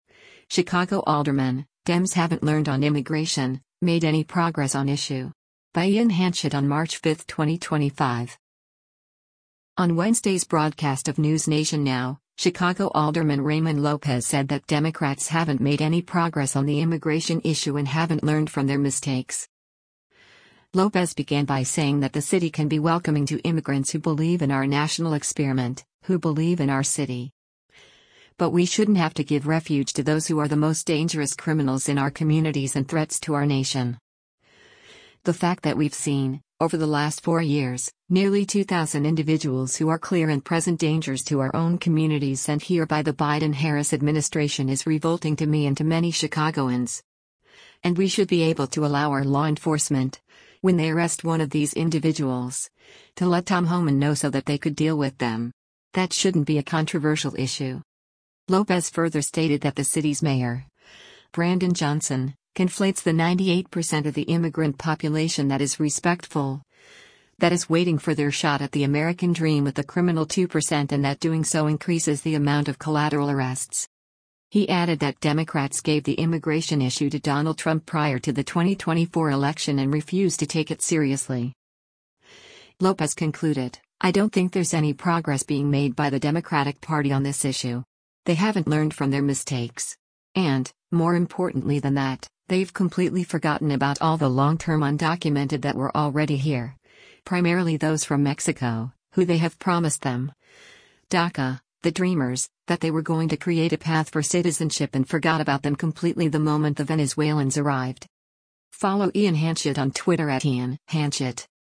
On Wednesday’s broadcast of “NewsNation Now,” Chicago Alderman Raymond Lopez said that Democrats haven’t made “any progress” on the immigration issue and “haven’t learned from their mistakes.”